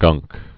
(gŭngk)